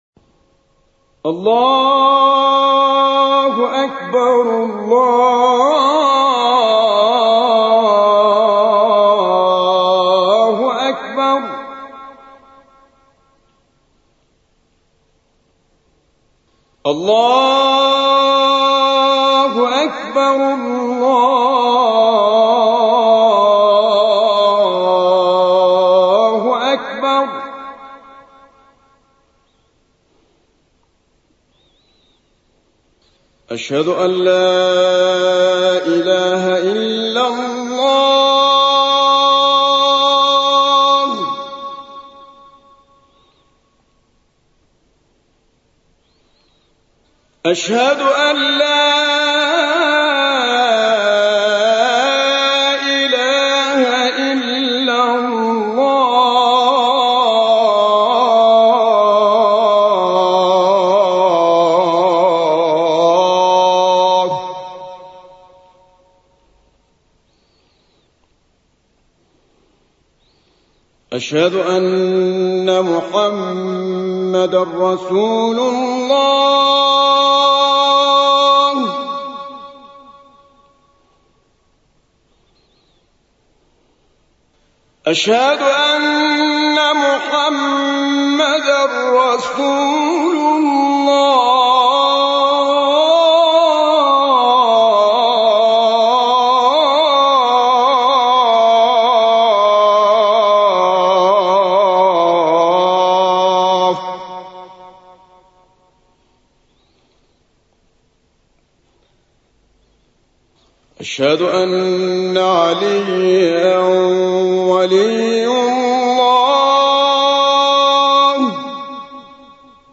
گروه فعالیت‌های قرآنی: گلبانگ اذان، با صدای دلنشین 9 قاری بین‌المللی را می‌شنوید.
اذان سید متولّی عبدالعال/ این اذان در مقام «رست» اجراء شده است.